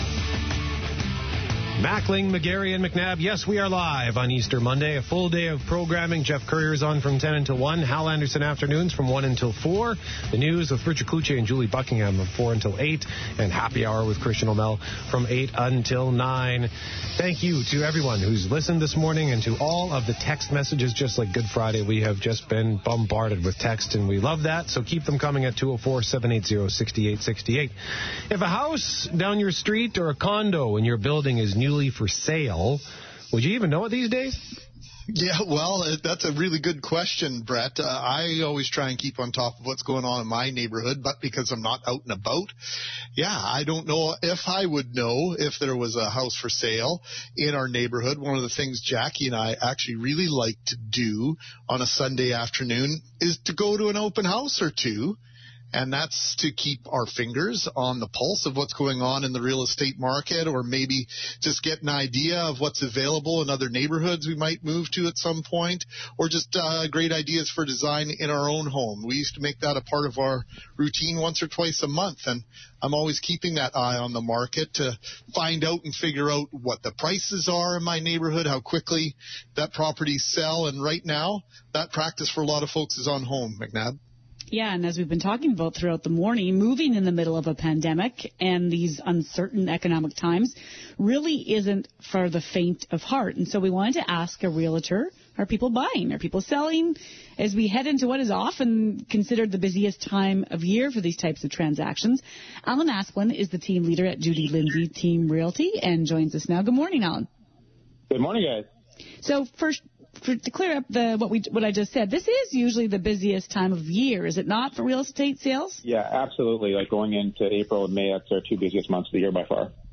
CJOB Real Estate Today Interview